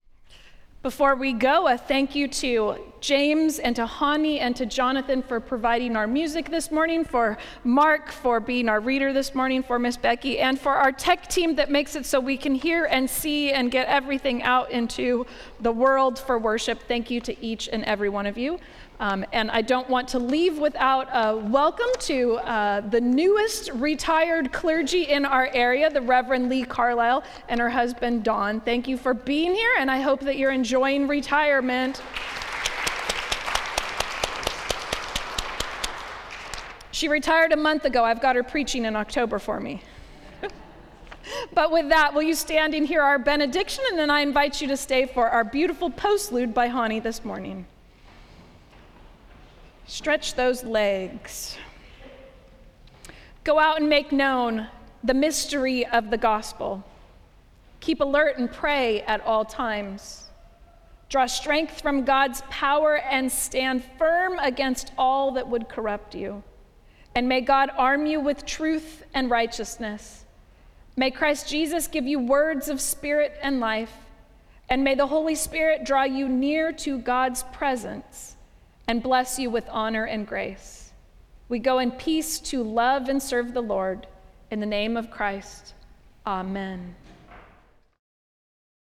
Service of Worship
Benediction